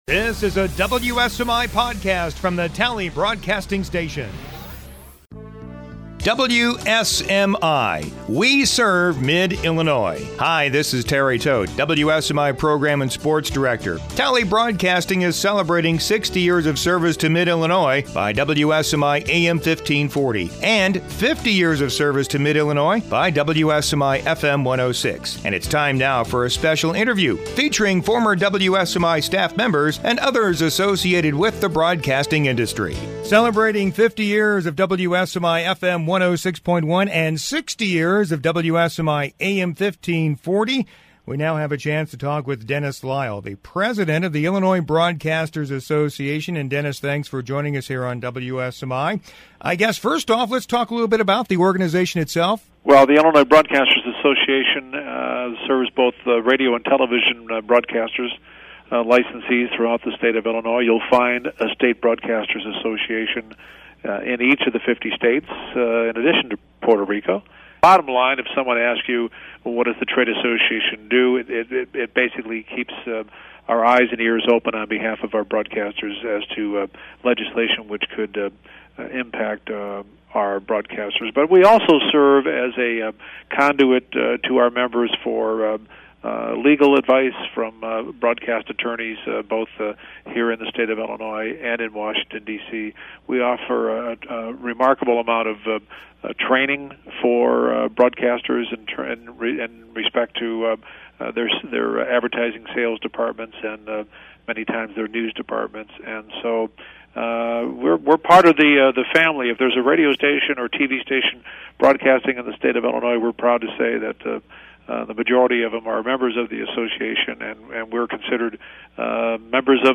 Podcasts - Interviews & Specials